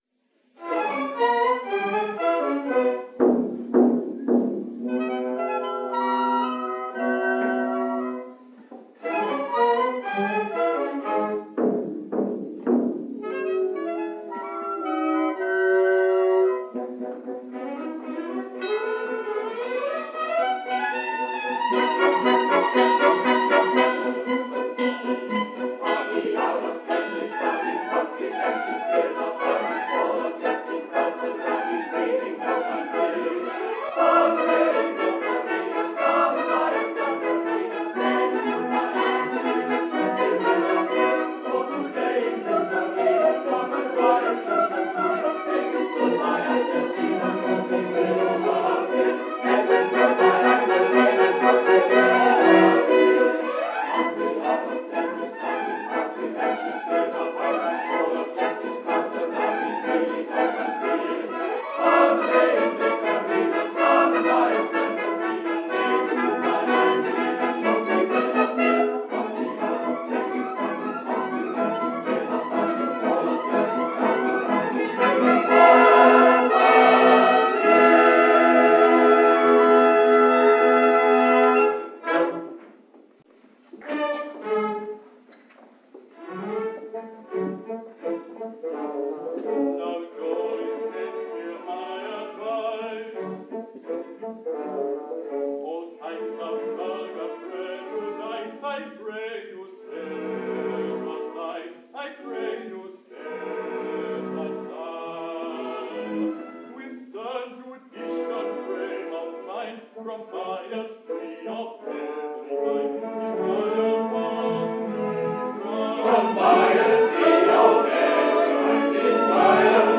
Another task I undertook was to record the performances for later distribution to the cast on audio cassette.
I can't recall the details of all the equipment used, but I believe I used a pair of AKG dynamic microphone belonging to the Dramatic Society. Some of the recordings used a Dolby 'B' noise reduction system for reel-to-reel tape built from a design in Wireless World.